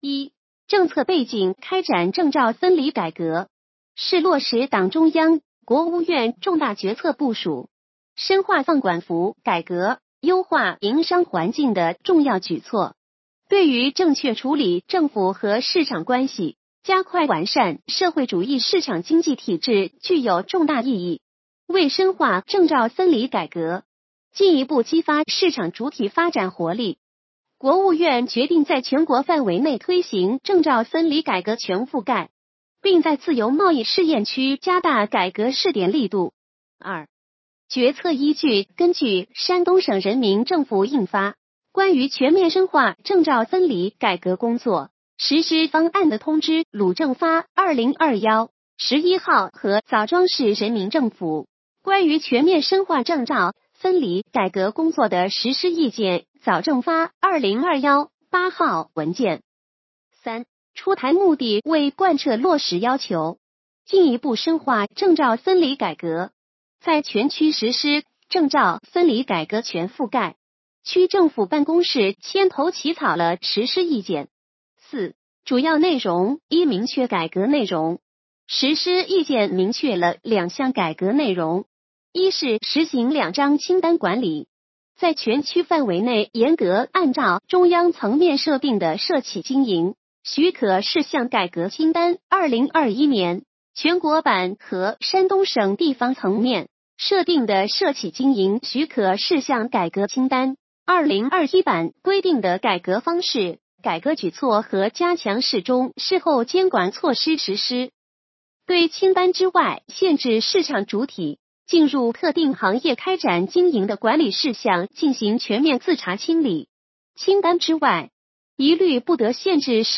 语音解读：山亭区人民政府关于全面深化证照分离改革工作的实施意见